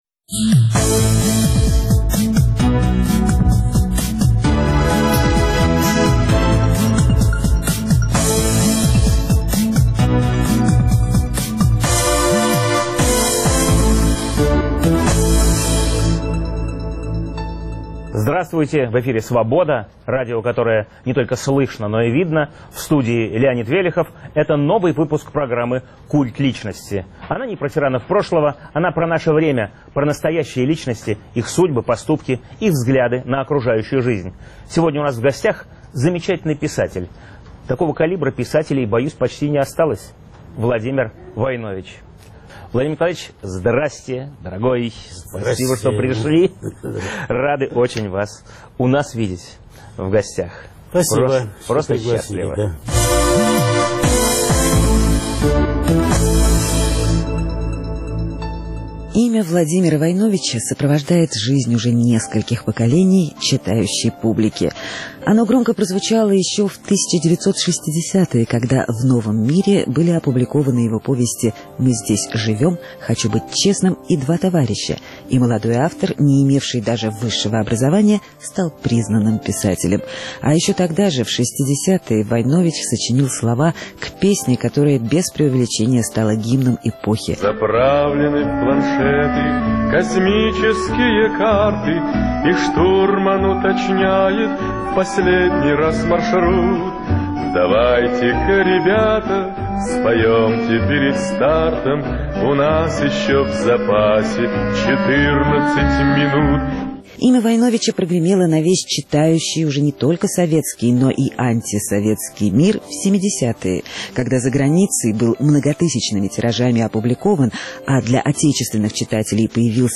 Новый выпуск программы о настоящих личностях, их судьбах, поступках и взглядах на жизнь. В гостях у "Культа личности" писатель Владимир Войнович.